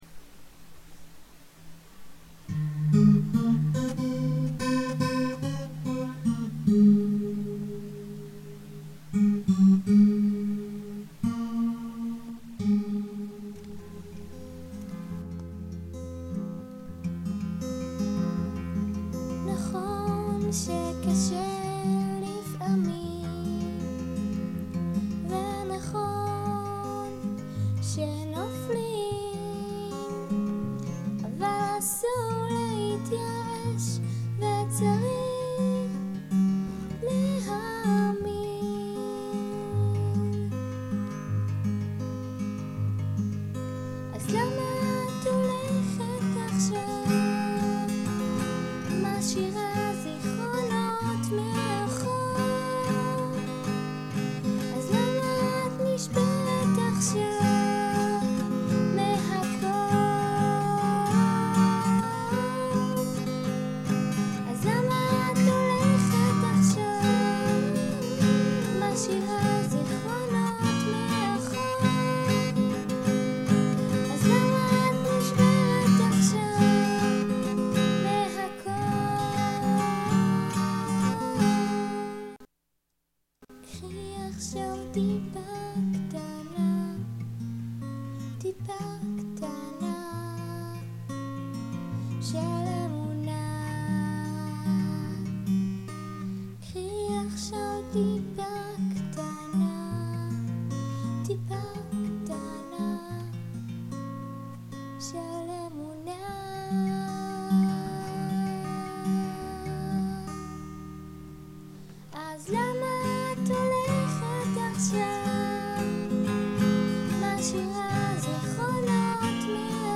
להאמין-שירת נשים[סקיצה ראשונית]